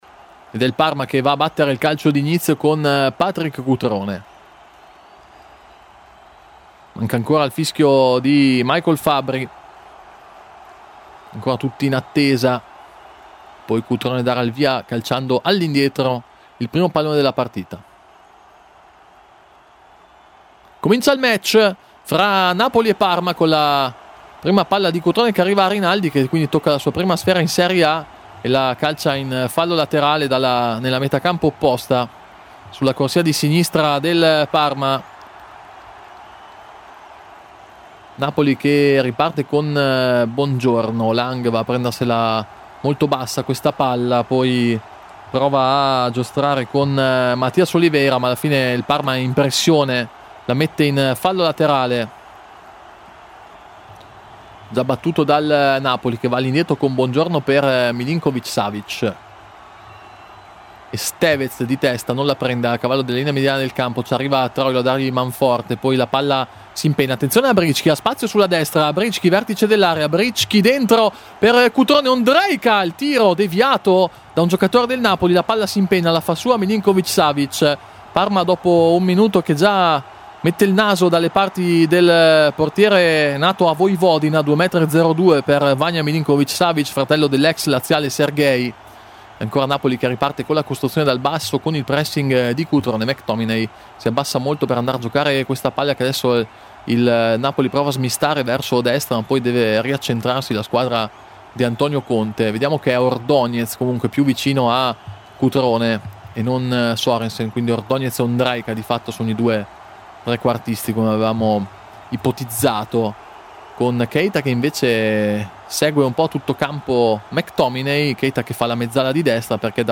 Radiocronache Parma Calcio Napoli - Parma 1° tempo - 14 gennaio 2026 Jan 14 2026 | 00:48:08 1x 00:00 / 00:48:08 Subscribe Share RSS Feed Share Link Embed